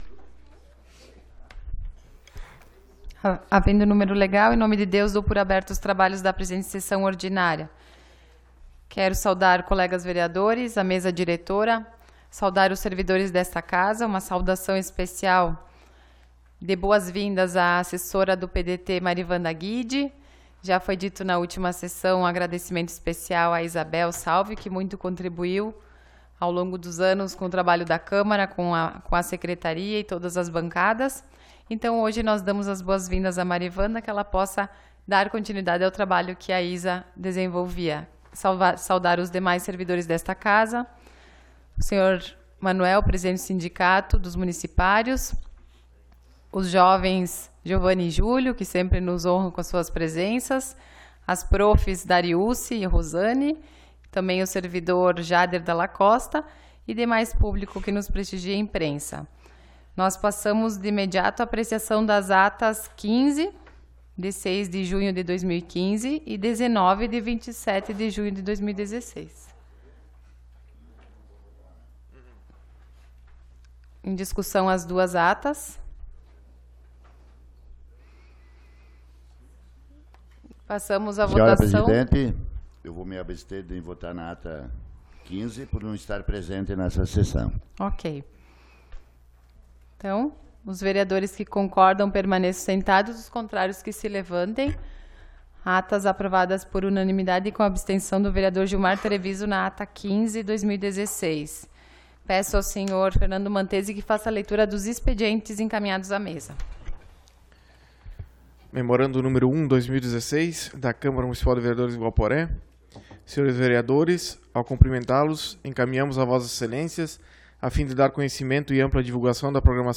Sessão Ordinária do dia 04 de Julho de 2016